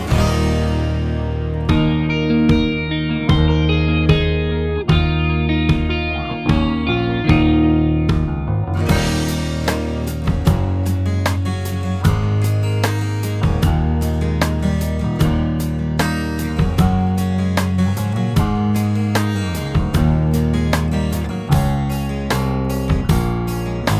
no Backing Vocals Rock 4:02 Buy £1.50